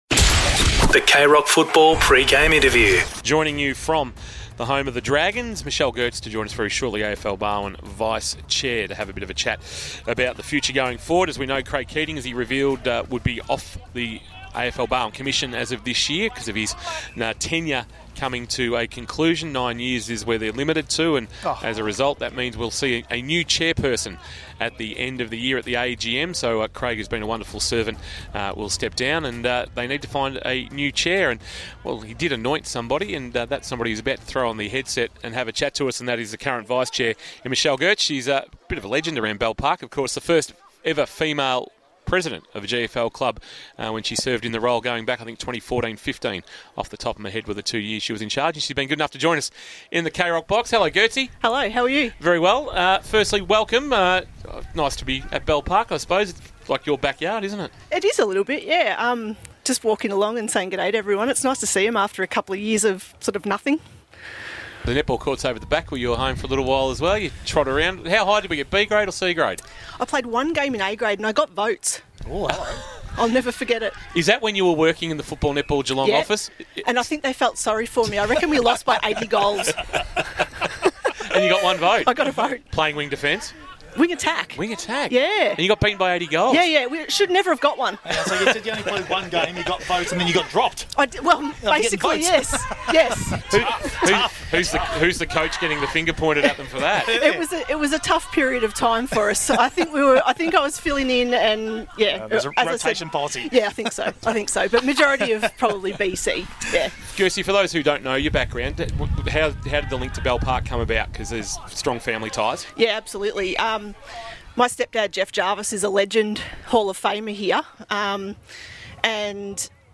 2022 - GFL ROUND 3 - BELL PARK vs. COLAC: Pre-match Interview